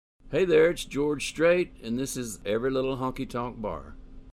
Audio / LINER George Strait (This is Every Little Honky Tonk Bar)